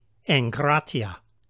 egkrateia
Pronunciation Note: When Gamma (γ) is followed by Kappa (κ), such as in the word ἐγκράτεια , the Gamma is pronounced like the ng in sing, and the Kappa is pronounced like the g in go.